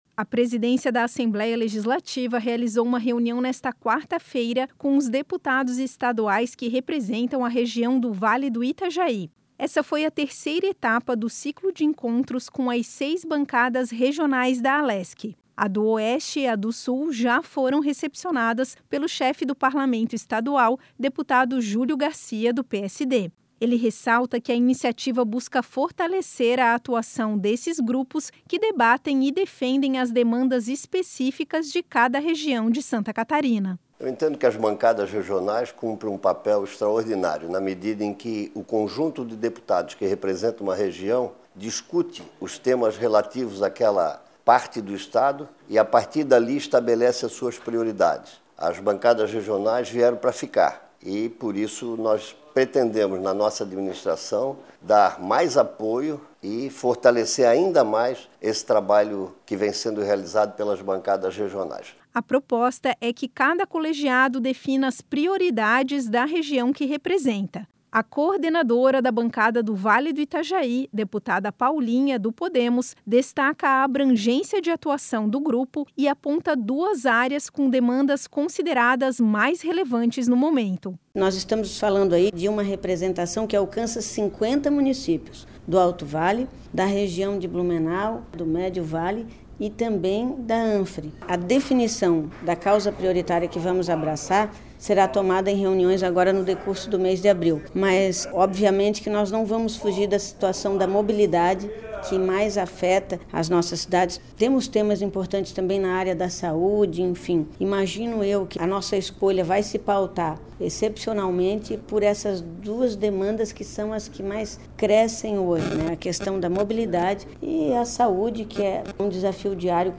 Entrevistas com:
- deputado Julio Garcia (PSD), presidente da Alesc;
- deputada Paulinha (Podemos), coordenadora da Bancada do Vale do Itajaí.